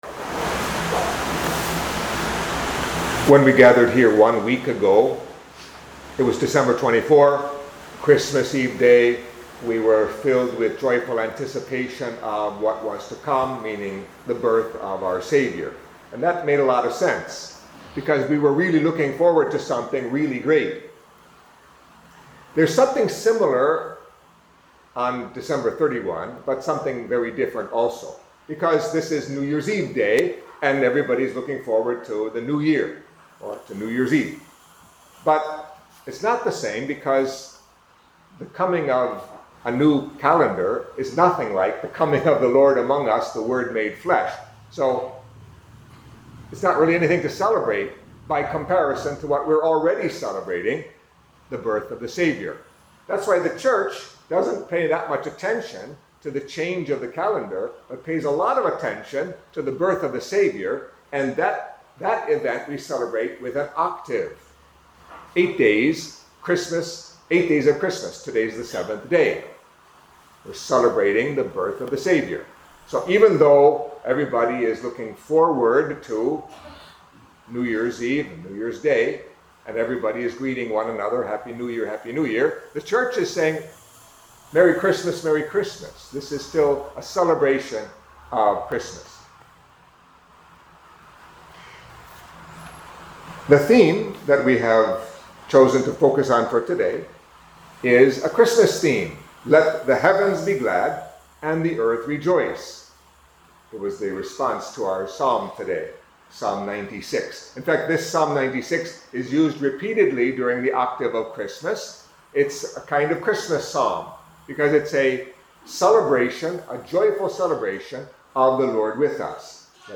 Catholic Mass homily for the Seventh Day in the Octave of Christmas